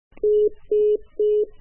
Call_end_dududu.mp3